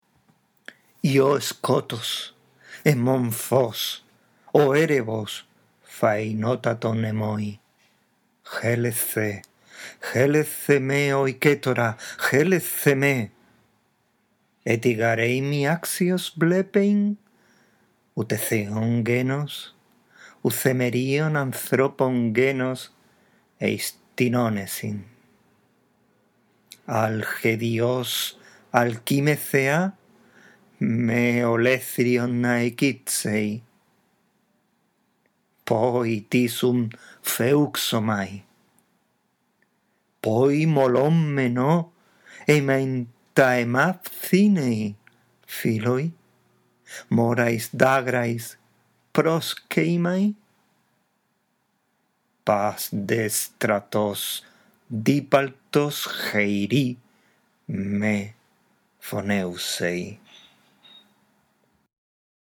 Esta audición del texto te guiará en tu práctica de lectura